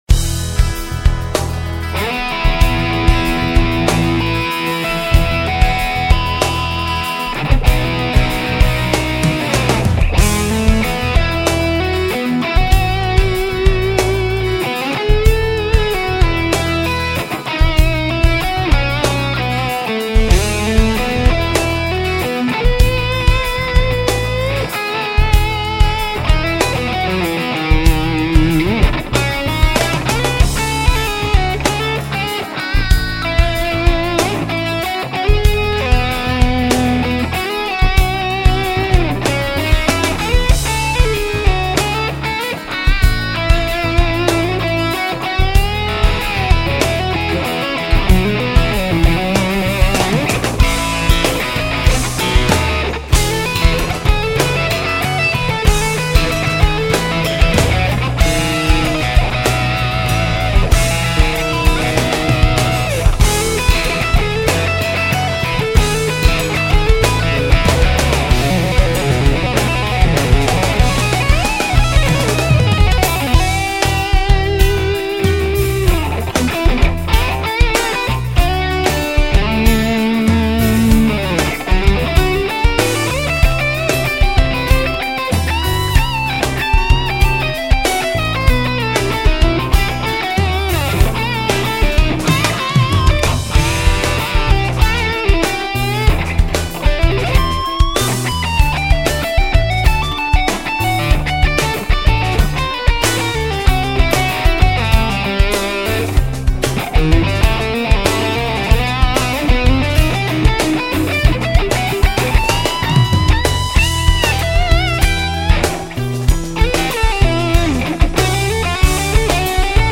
Un peu de gratte :D - Page 5 - COMPOSITEUR .ORG
un playback style satriani